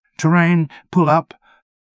terrain-pull-up.wav